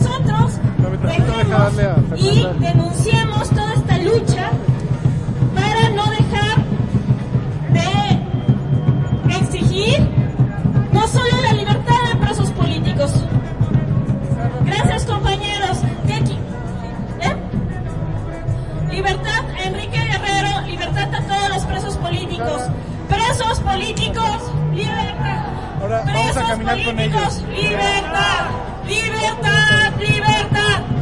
描述：1968年，为了纪念被杀害的学生，墨西哥的一群暴徒......街道，人群，学生，人，墨西哥，西班牙语的一切
Tag: 人群 抗议 暴民